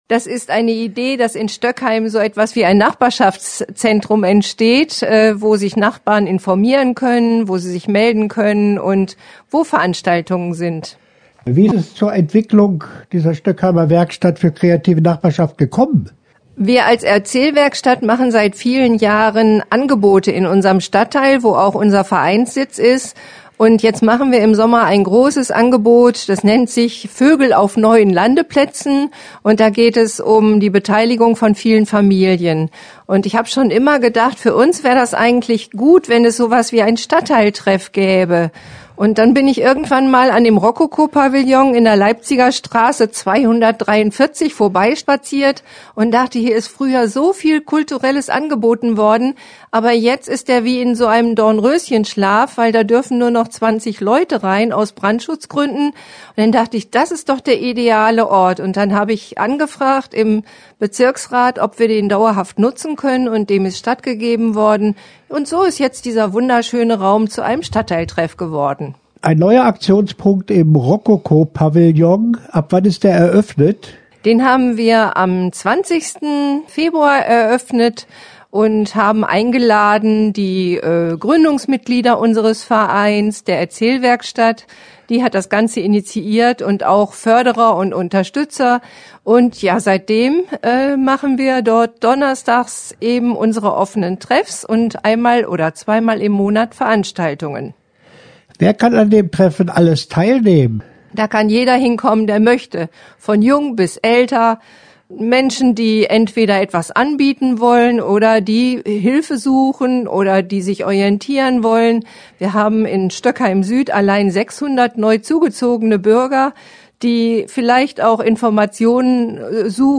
Interview-Stoeckwerk.mp3